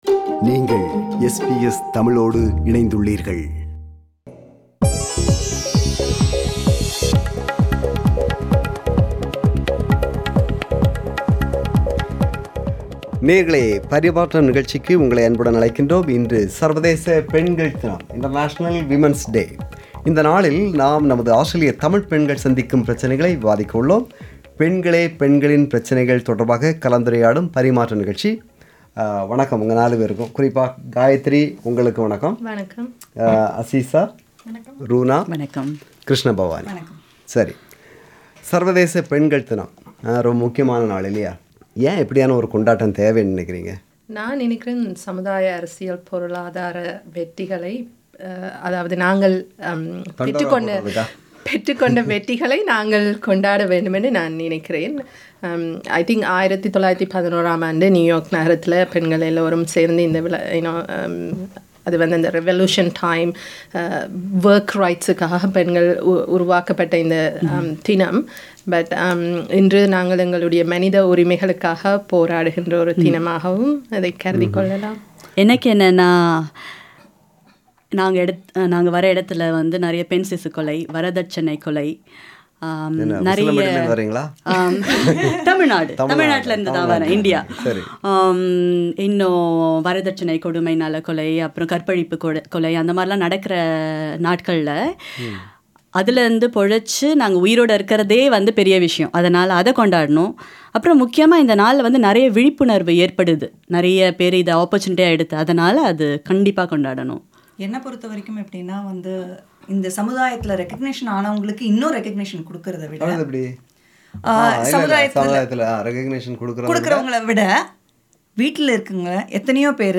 Panel discussion on women's issues